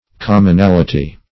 commonality \com`mon*al"i*ty\ n.